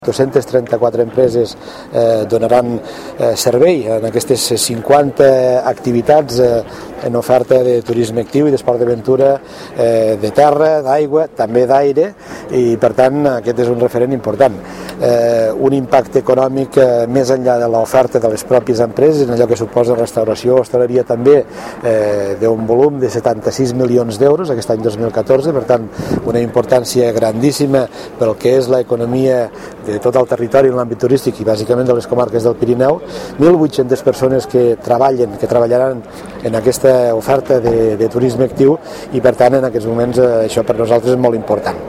Així ho ha manifestat Reñé aquest dimecres en la presentació de la nova temporada, que s’ha fet a Llavorsí coincidint amb les vacances de Setmana Santa.